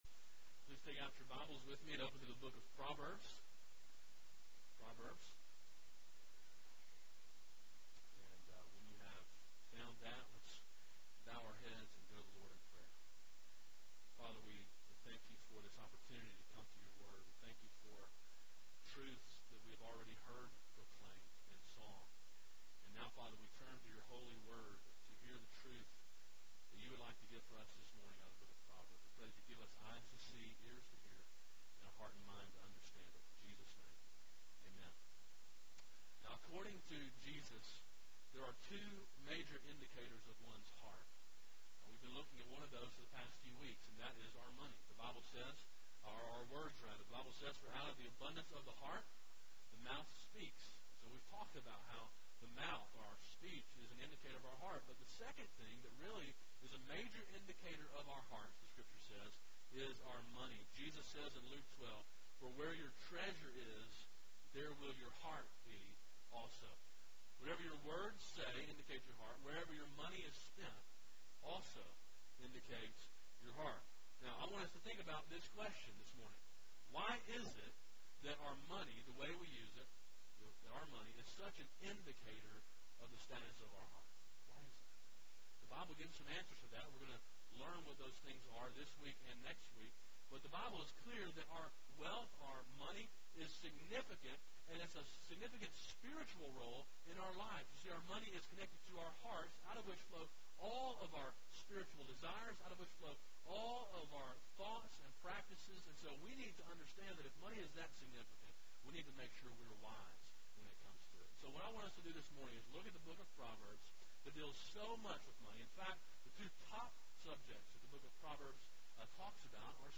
A sermon in a series on the book of Proverbs.